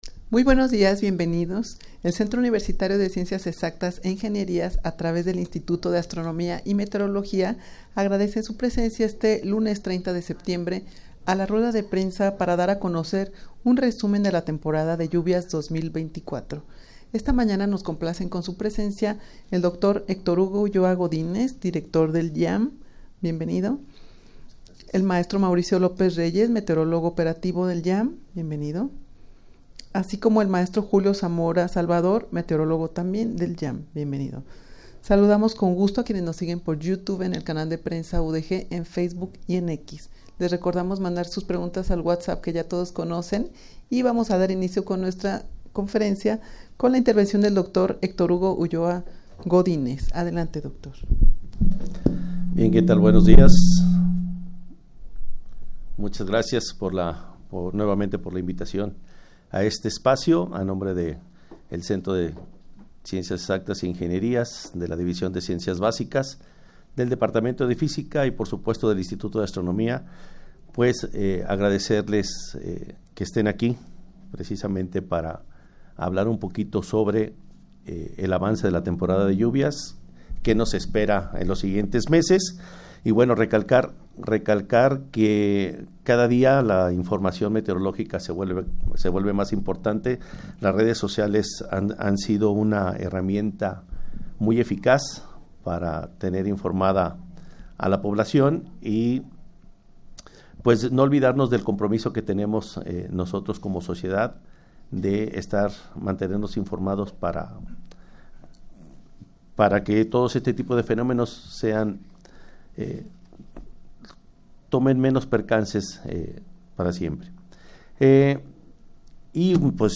rueda-de-prensa-del-instituto-de-astronomia-y-meteorologia-para-dar-un-balance-del-temporal-de-lluvias-2024_0.mp3